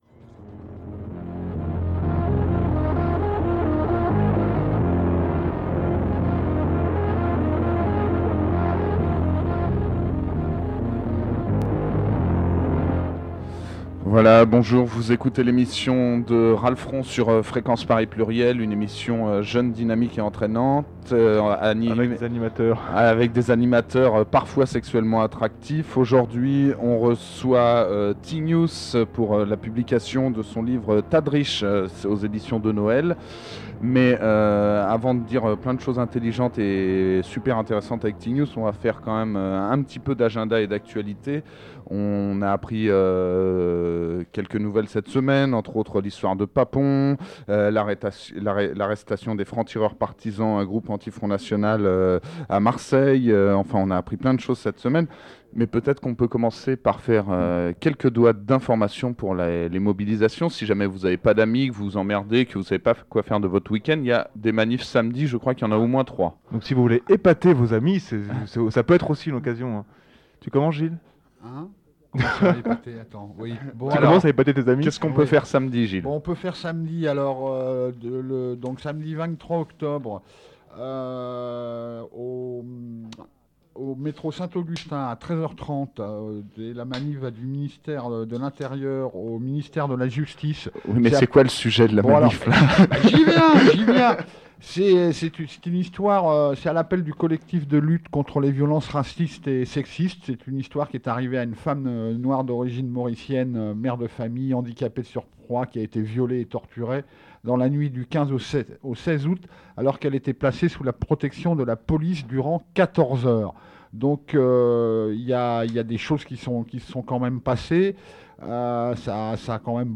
Emission en compagnie du dessinateur Tignous à l'occasion de la parution de son nouvel album, Tas de Riches